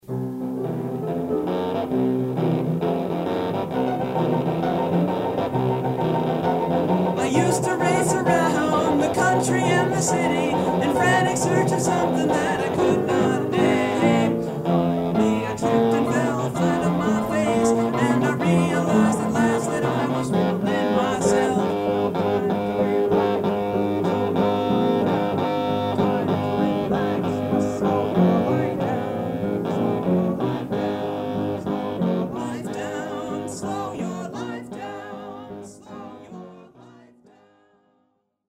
(original demo)